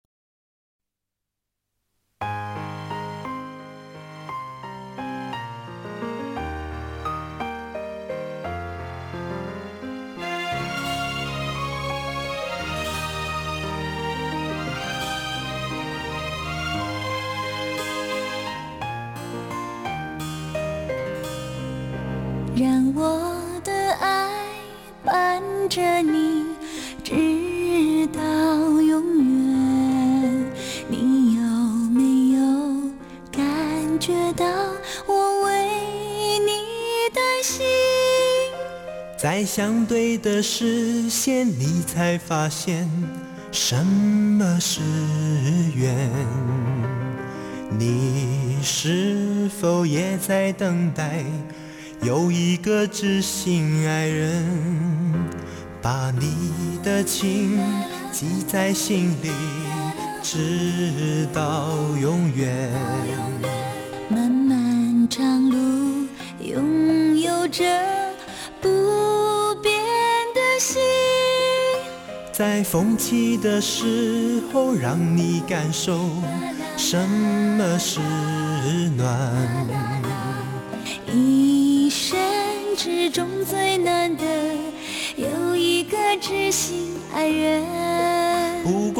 高亢的嗓音，投入的表演，